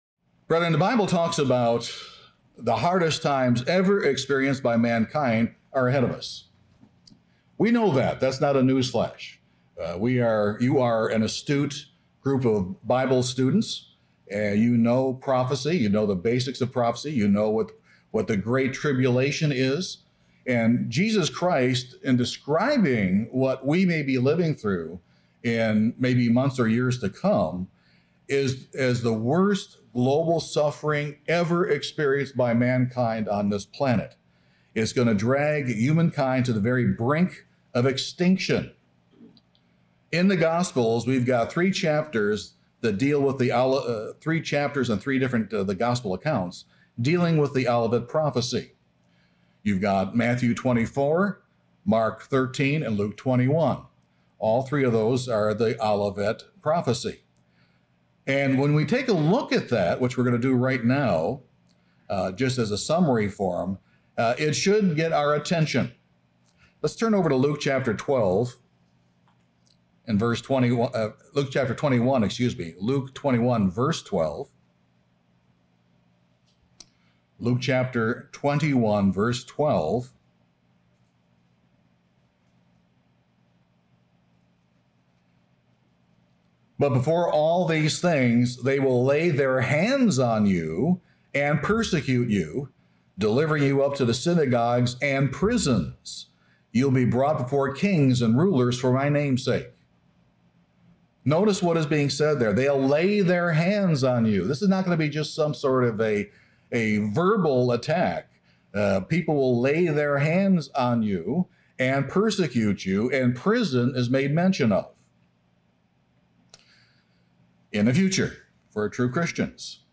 This sermon discusses how we can make the proper spiritual preparations.